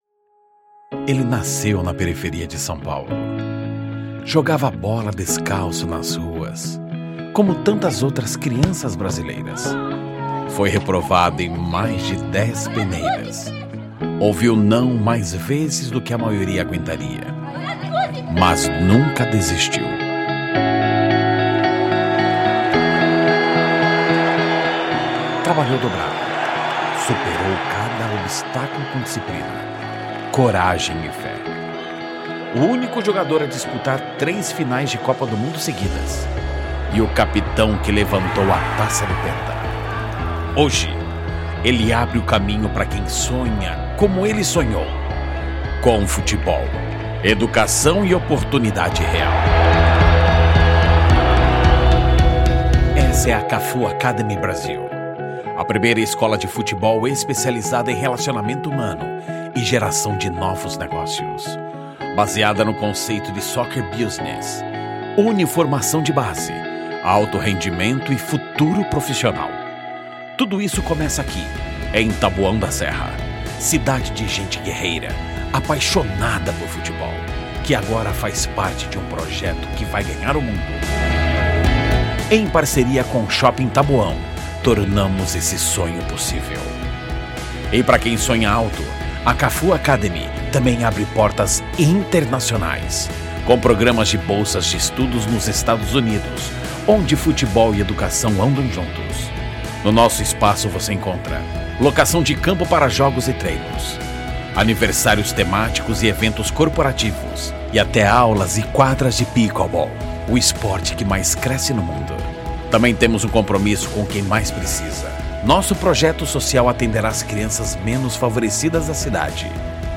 DEMONSTRATIVO VSL:
Padrão